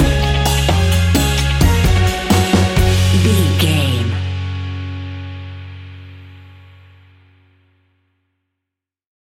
A warm and upbeat piece of calypso summer sunshine music!
That perfect carribean calypso sound!
Uplifting
Ionian/Major
F#
steelpan
calypso music
drums
percussion
bass
brass
guitar